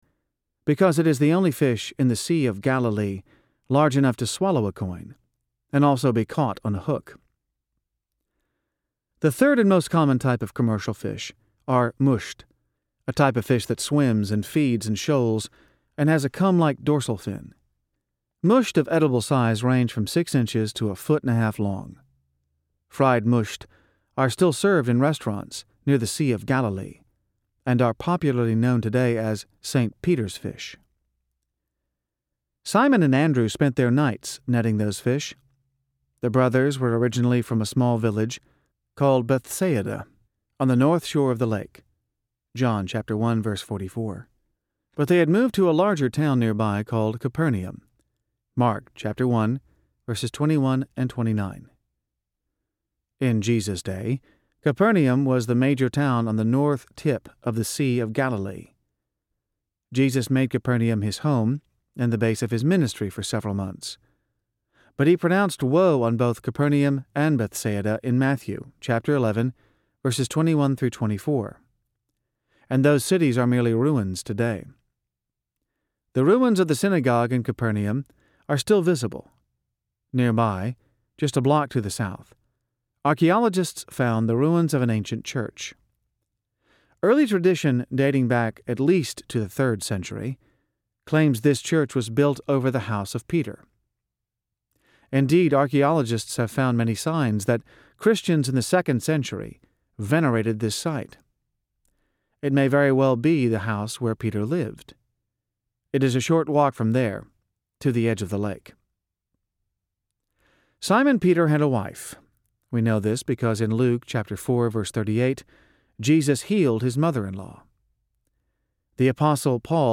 Twelve Ordinary Men Audiobook
Narrator
7 Hrs. – Unabridged